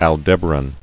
[Al·deb·a·ran]